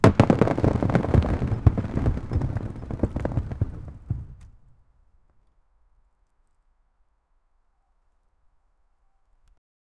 Massive boulder crashes and ground rumbles
massive-boulder-crashes-a-uabsi7yy.wav